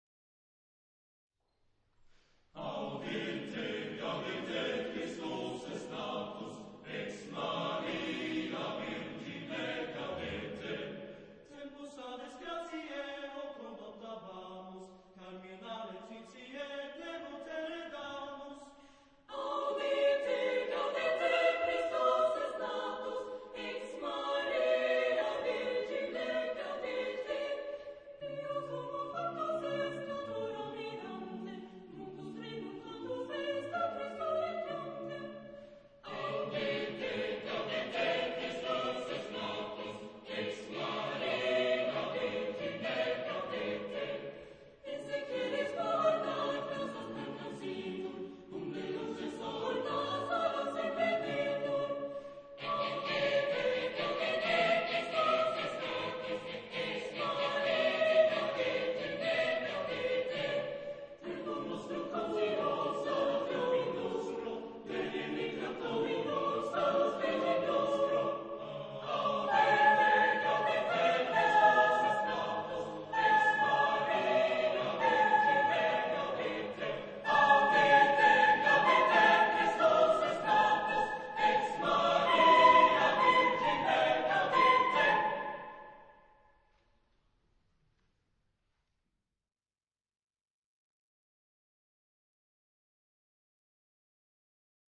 recorded during the "11th International Chamber Choir Competition Marktoberdorf 2009"
Type of choir: SSAATTBB (8 mixed voices)
Genre - Style - Musical FORM: Sacred; Secular; Medieval
Mood of the piece: joyous; fast
Tonality: C sharp dorian
Keywords: a cappella, Christmas, Jesus Christ, Virgin Mary, birth, rejoicing